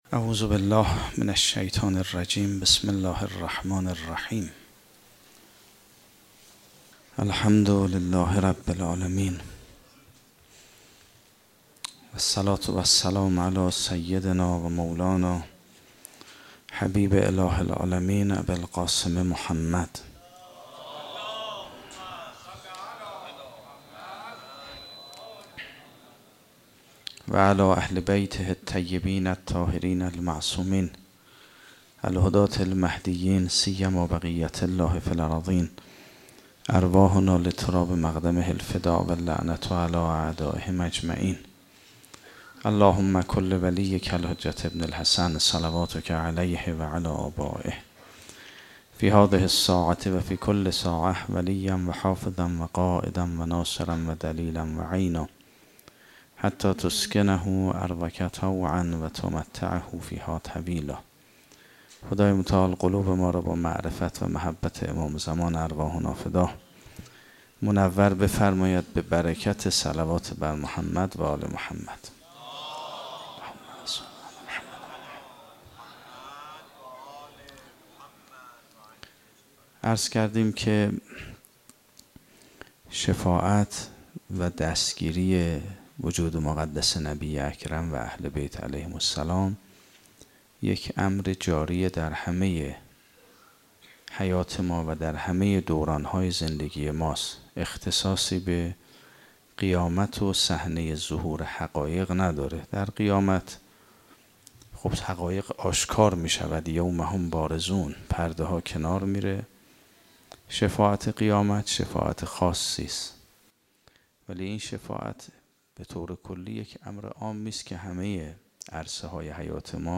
مجموعه سخنرانی های حضرت حجه الاسلام والمسلمین استاد میرباقری را که درماه صفرالمظفر1435 درحسینیه آیت الله العظمی مرعشی نجفی (ره) ایراد شده را به ترتیب درلینکهای زیر بیابید.